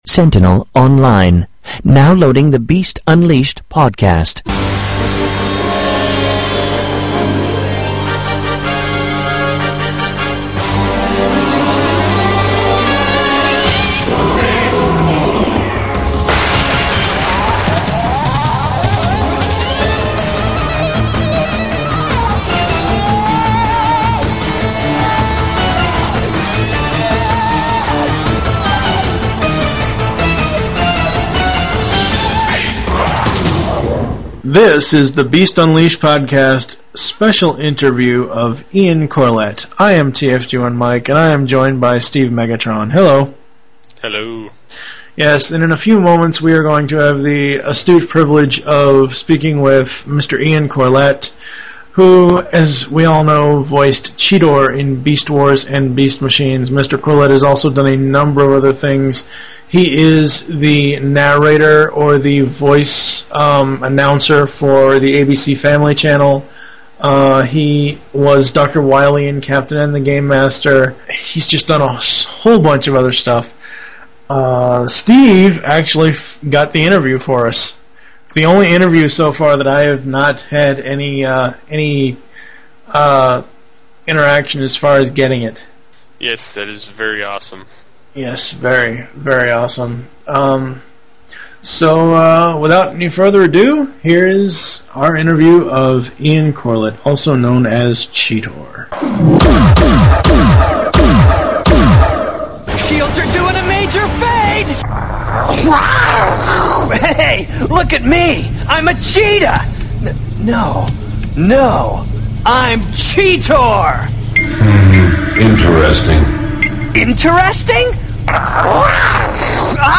Interviews – Ian James Corlett